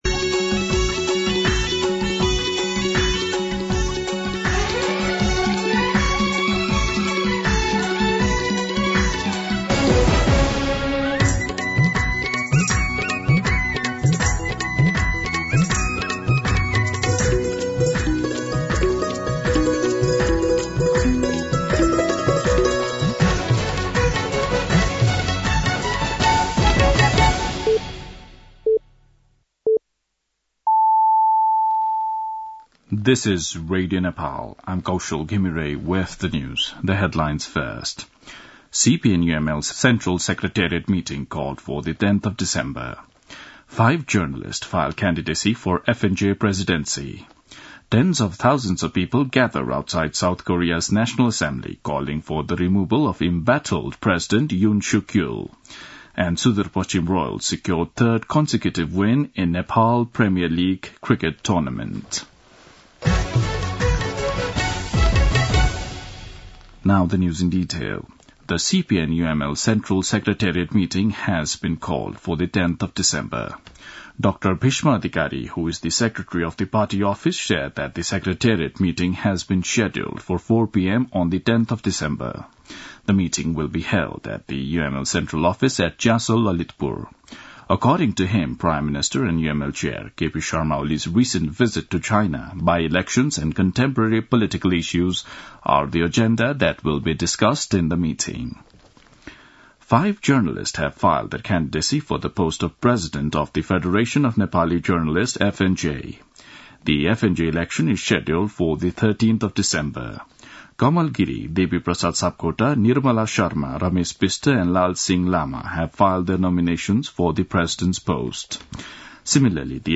दिउँसो २ बजेको अङ्ग्रेजी समाचार : २३ मंसिर , २०८१
2-pm-English-News.mp3